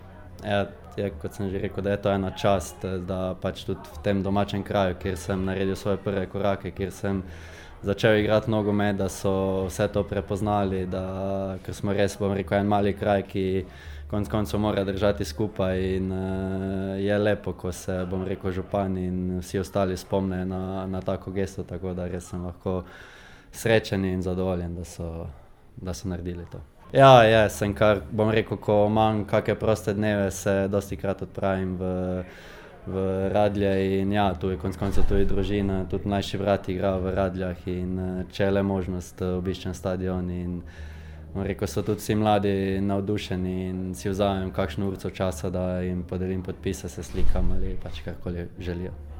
Tako je zatrdil na slovesnosti ob poimenovanju radeljskega stadiona po njem.
izjava Zan Karnicnik .mp3